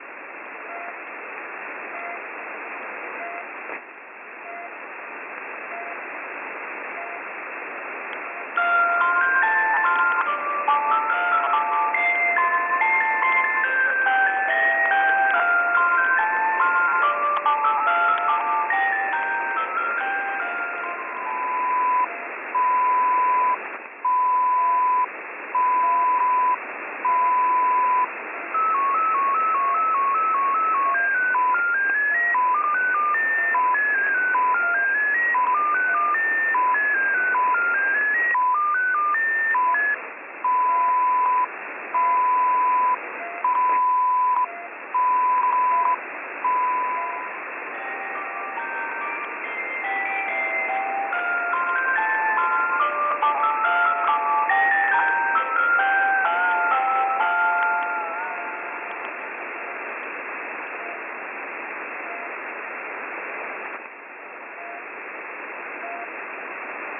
Oulun alueella on viime aikoina havaittu omituista radioliikennettä.
Olemme onnistuneet kaappaamaan neljä lähetystä, joihin uskomme sisältyvän salaisen viestin.